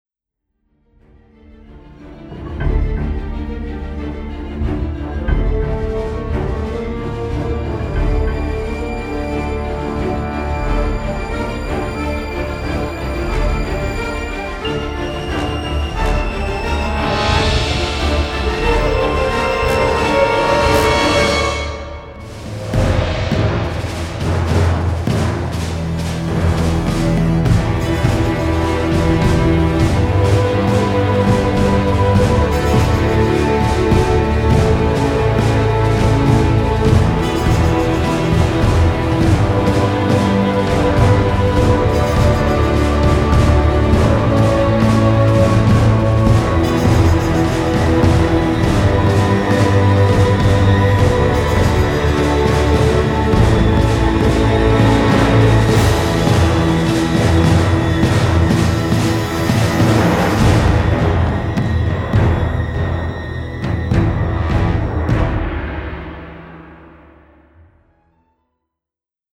with a tense and modern sheen.